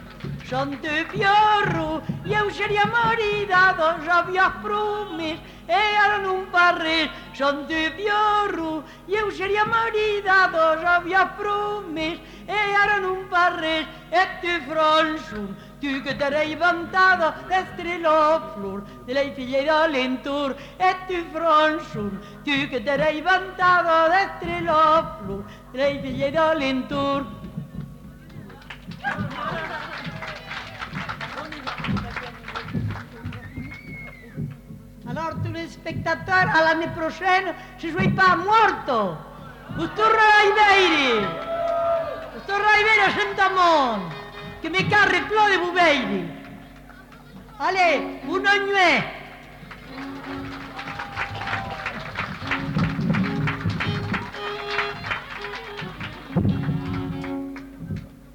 Lieu : Saint-Amans-des-Cots
Genre : chant
Effectif : 1
Type de voix : voix de femme
Production du son : chanté
Classification : danses